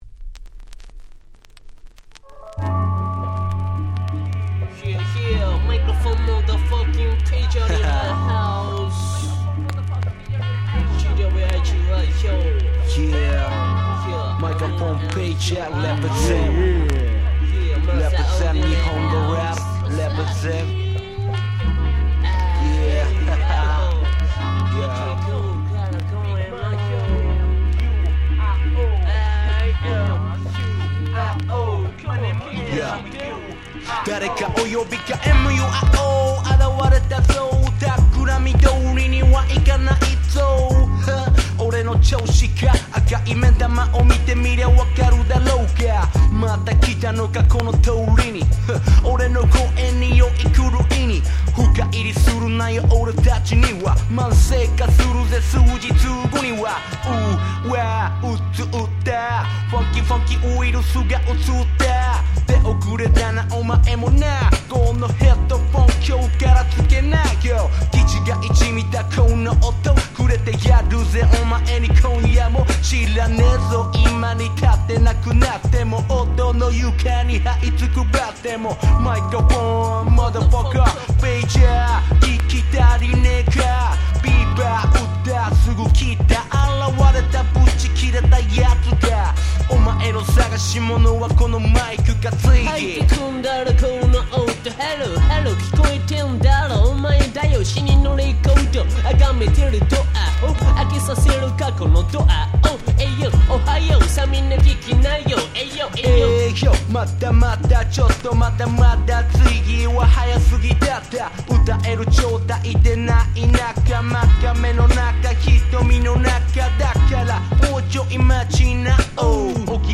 94' Japanese Hip Hop Super Classics !!
説明不要の90's 日本語ラップクラシックスです。